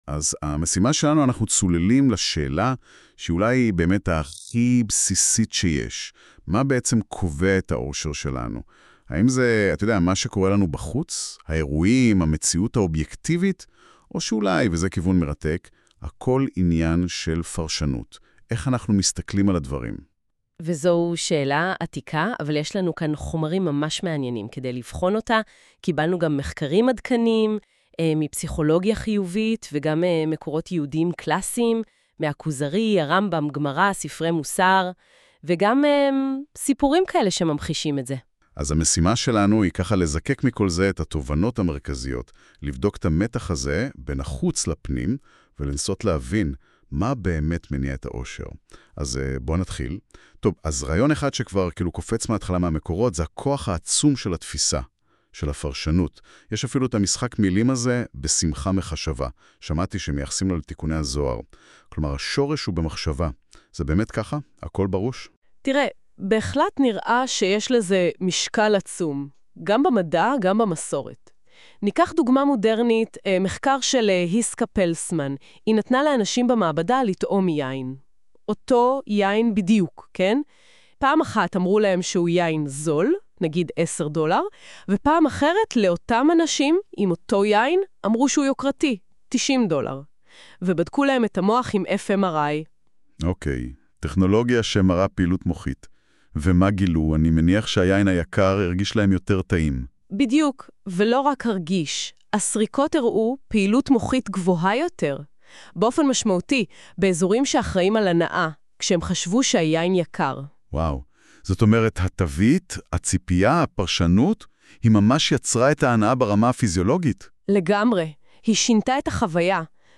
שיעור מרתק - האושר: הכל בראש?